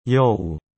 Como acertar na pronúncia de 여우?
Divida a palavra em duas partes: “여” (yeo), que soa como “ió”, e “우” (u), com som de “u” em “luz”. Quando juntar, fale suavemente: “ió-u”.